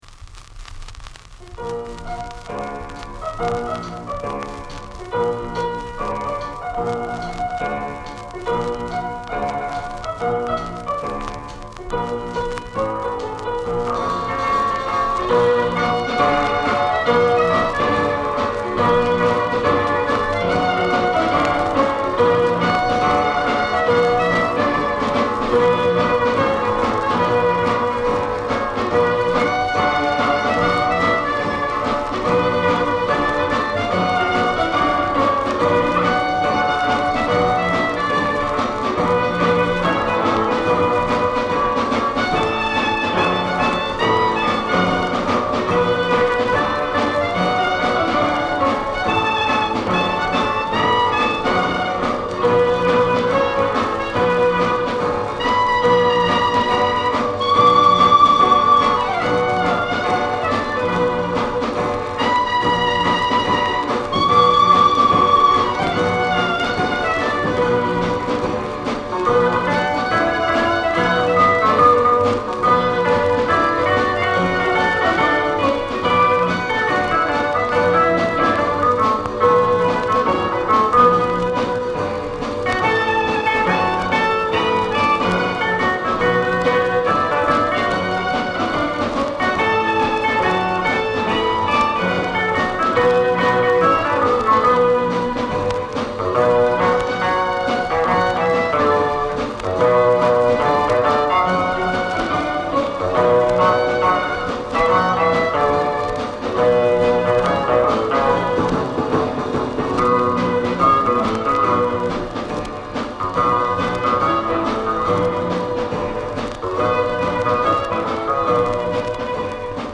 guitare
batterie
clarinette
piano
sur un gros magnétophone à voyants